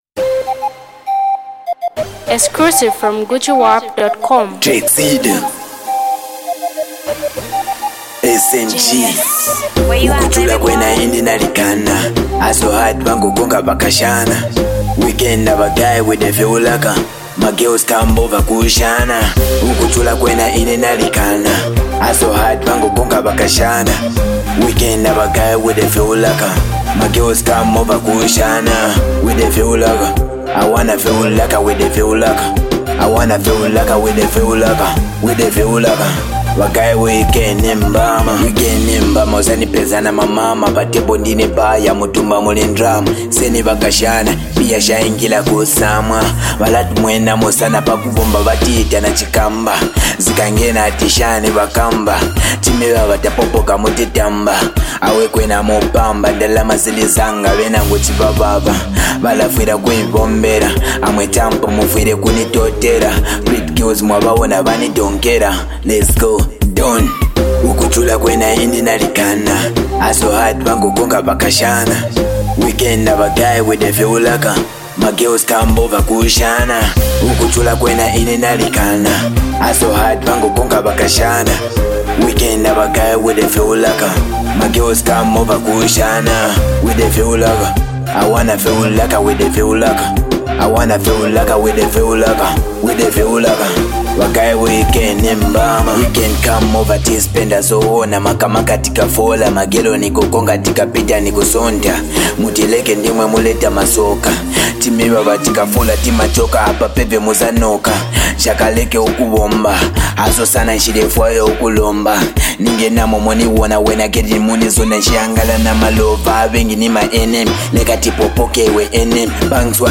rap sensation artist, singer and songwriter
a very powerful melodic hit jam